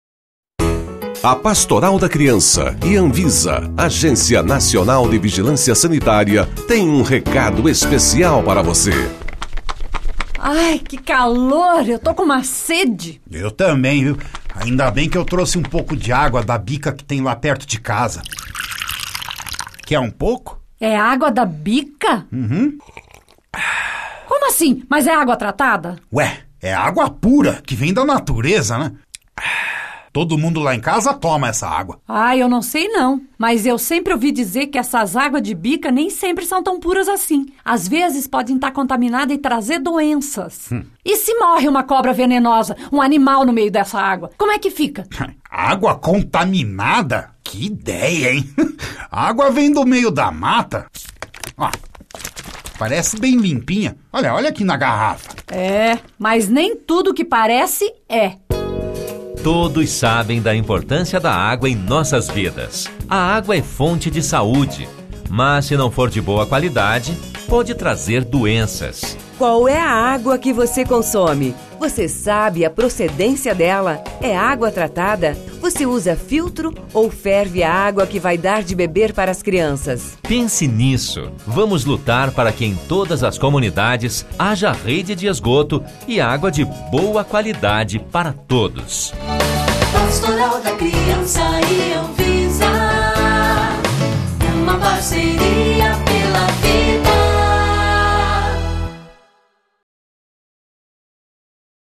Vinhetas ANVISA - Alimentação, higiene e saúde